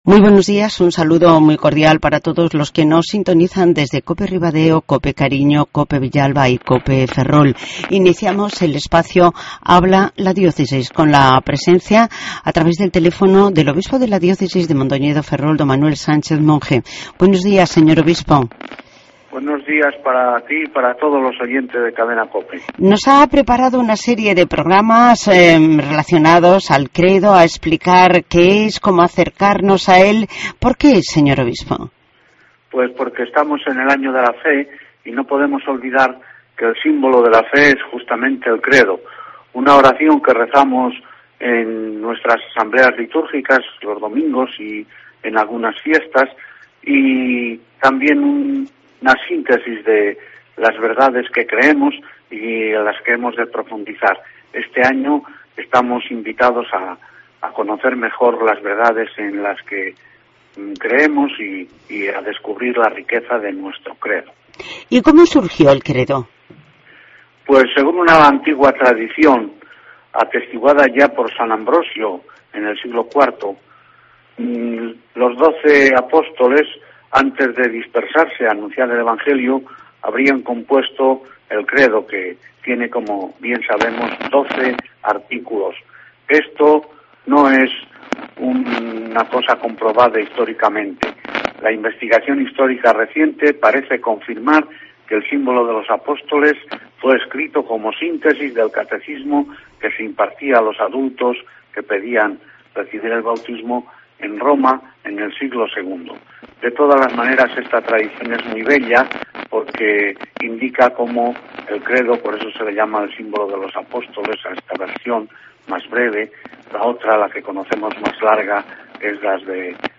Redacción digital Madrid - Publicado el 21 abr 2013, 12:43 - Actualizado 14 mar 2023, 18:32 1 min lectura Descargar Facebook Twitter Whatsapp Telegram Enviar por email Copiar enlace Tema: El Obispo de la Diócesis de Mondoñedo-Ferrol, Monseñor Sánchez Monge, aborda una amplia definición del Credo, el símbolo de la Fe.